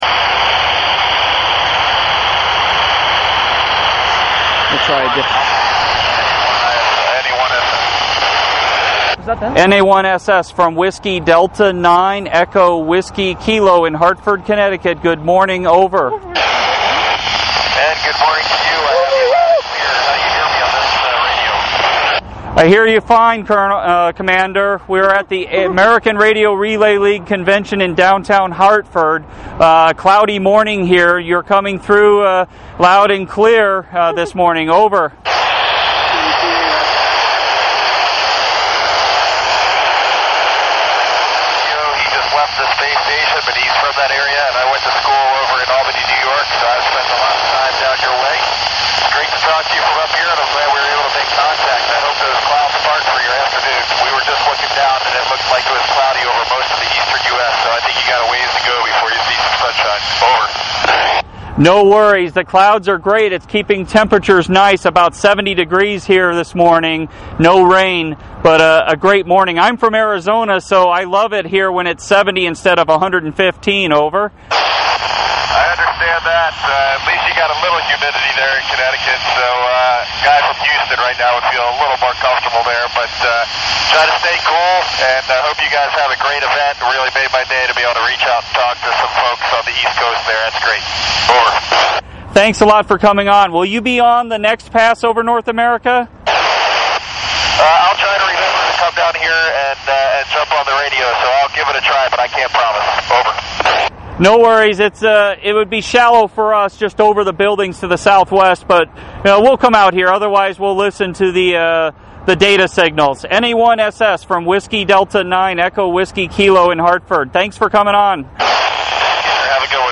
NA1SS QSO at 2014 ARRL Centennial Convention - 19 July 2014 @ 1419 UTC